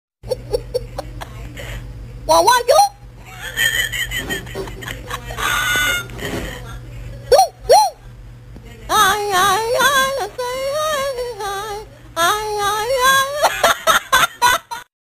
Genre: Nada dering lucu